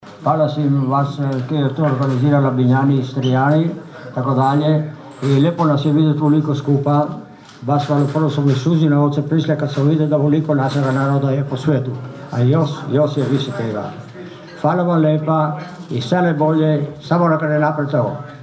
Stotinjak iseljenika koji trenutačno borave u domovini, uglavnom iz SAD-a, ali i Kanade te europskih zemalja Francuske i Njemačke, okupilo se sinoć u Konobi 'Bukaleta' na Dubrovi, na Godišnjem susretu iseljenika Labinštine.